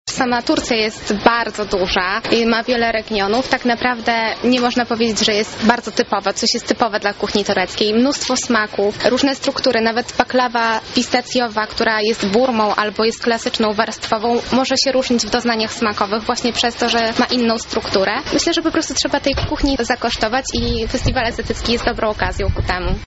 Na placu przed Centrum Spotkania Kultur od kilku dni można było poczuć orientalne zapachy.